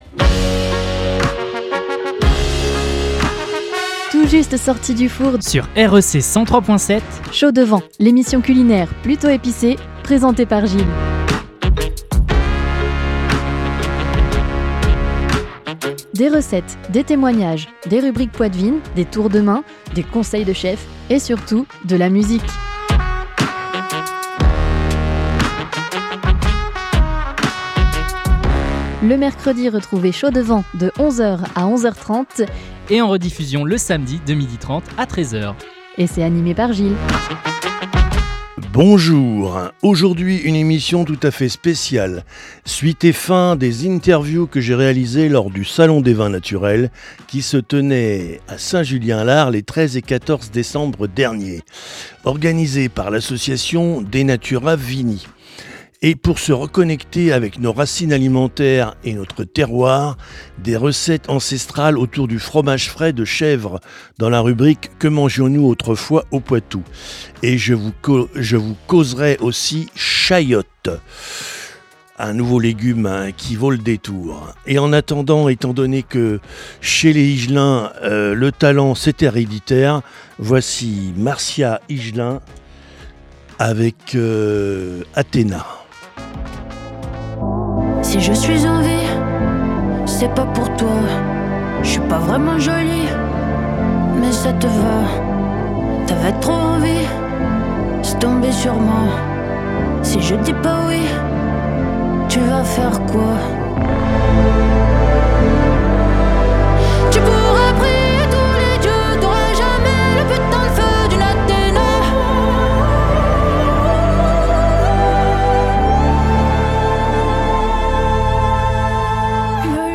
Aujourd’hui en début d’émission vous ne pourrez pas échapper , à une émission spéciale , suite et fin des interviews , que j’avais réalisé lors du salon des vins naturels de Saint Julien Lars , les 13 et 14 décembre dernier organisé par l’association DE NATURA VINI . Effectivement vous retrouverez les interviews : d’un viticulteur de Touraine , d’un artisan boulanger en BIO et d’une maraîchère éleveuse d’Ovin .